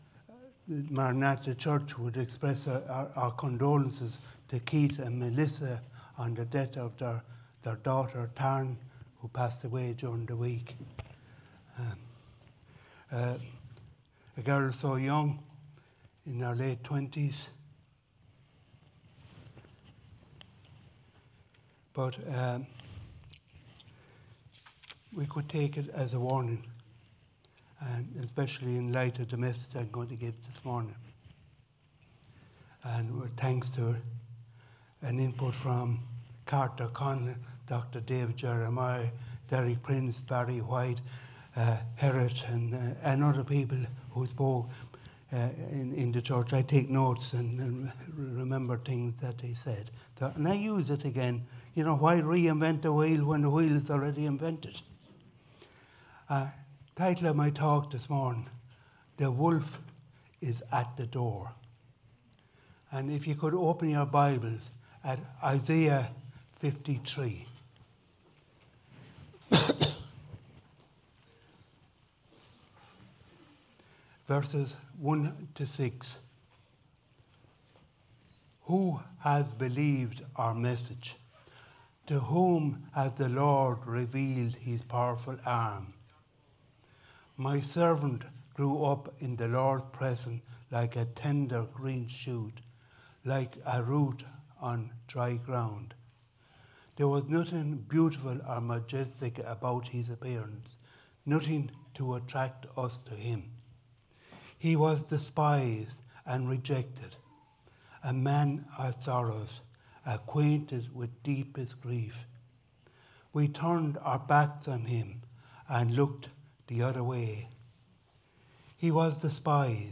A message from the series "Messages 2026."